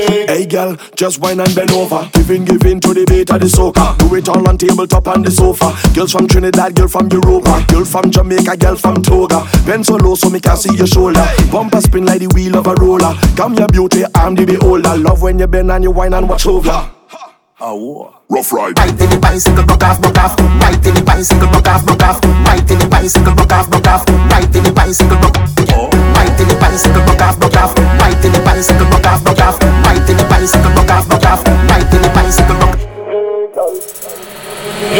Жанр: Танцевальная музыка
# Modern Dancehall